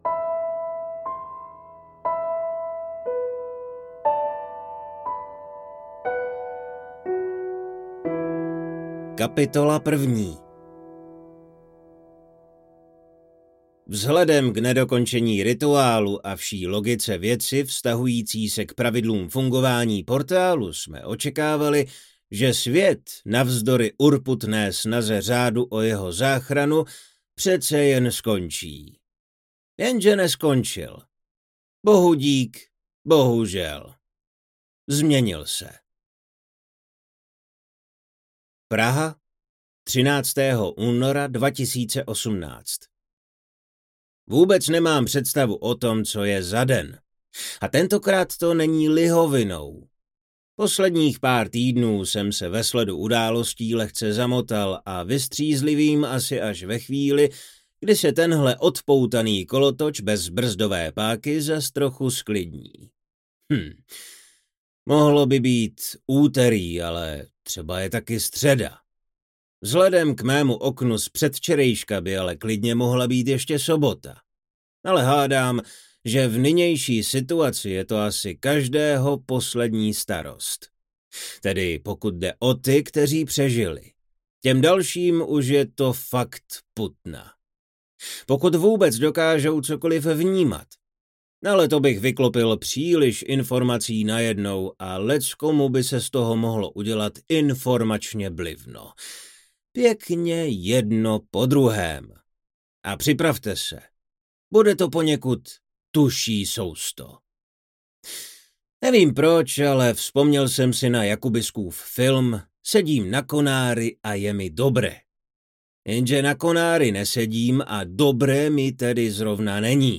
Hudba: Ondřej Morcinek